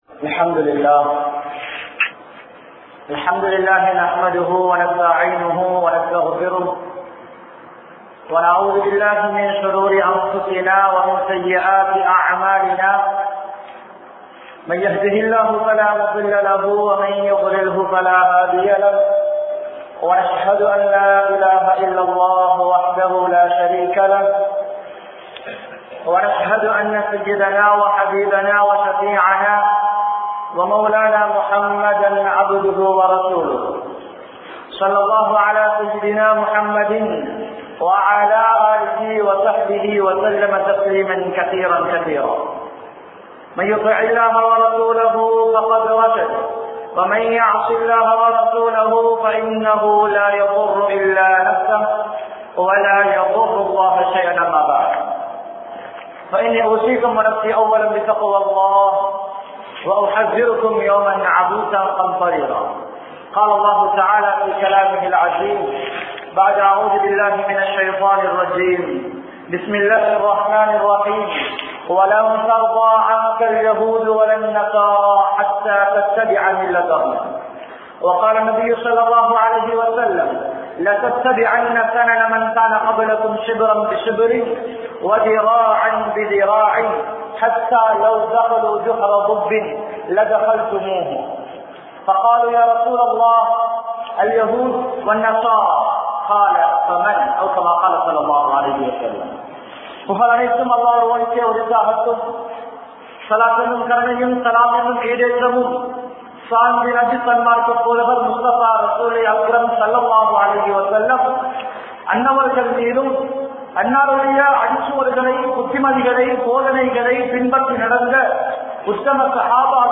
April Foolum Kiristhawarhalin Enaiya Kondaattangalum(ஏப்ரல் பூல்உம் கிறிஸ்தவர்களின் ஏனைய கொண்டாட்டங்களும்) | Audio Bayans | All Ceylon Muslim Youth Community | Addalaichenai